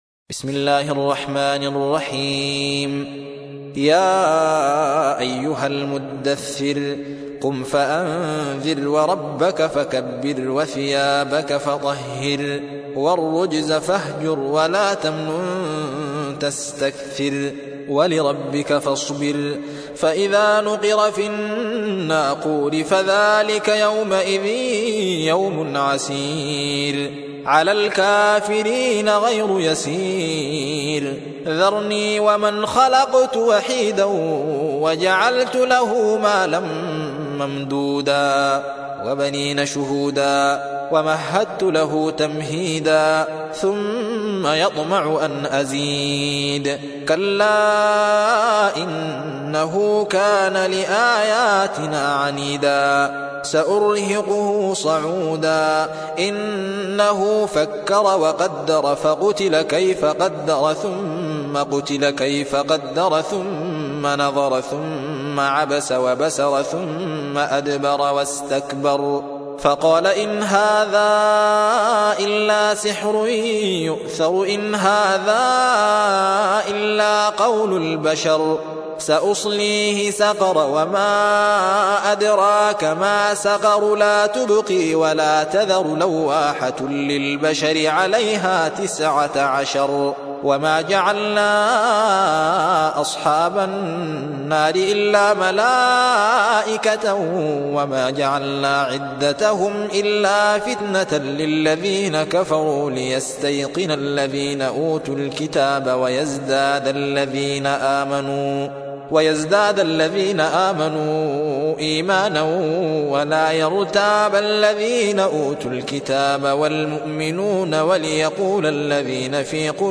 سورة المدثر / القارئ